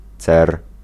Ääntäminen
Ääntäminen Tuntematon aksentti: IPA: /t͡sɛr/ Haettu sana löytyi näillä lähdekielillä: puola Käännös Ääninäyte 1. cerium {n} Suku: m .